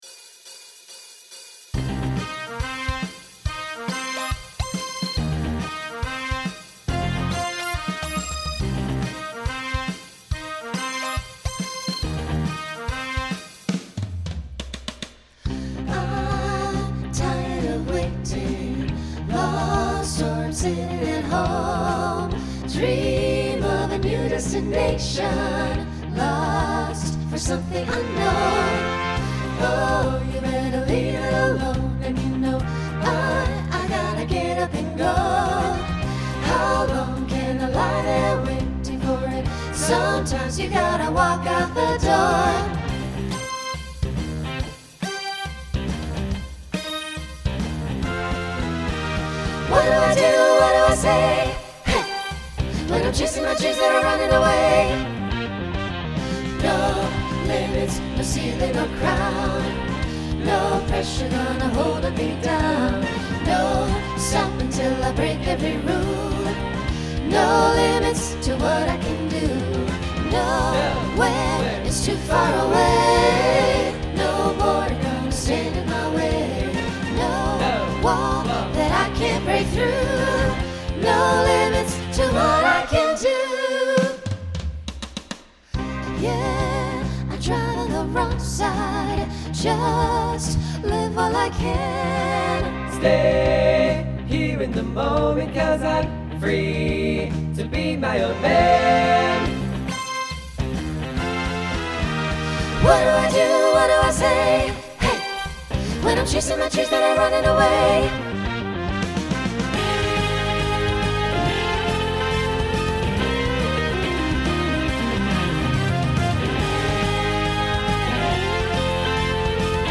Genre Rock Instrumental combo
Closer Voicing SATB